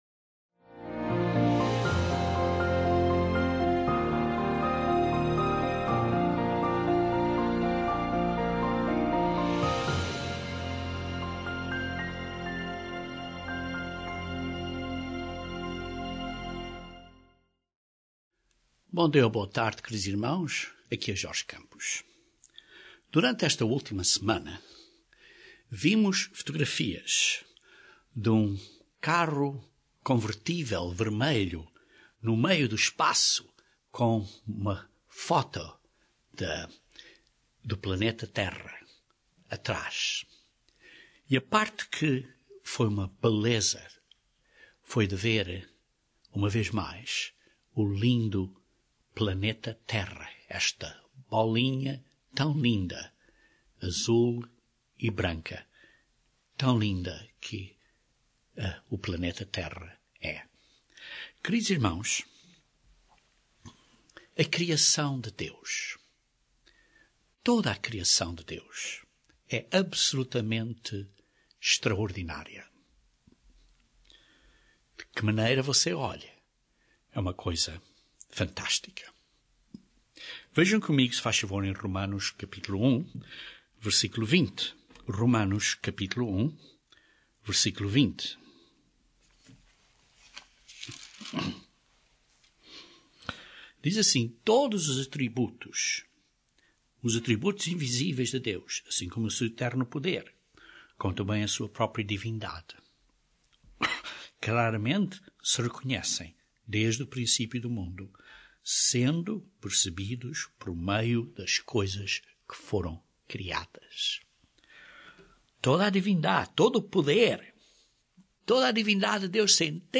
Deus usa a Sua criação física para entendermos as coisas invisíveis. Este sermão descreve dois exemplos de sombras físicas que têm grande significado espiritual.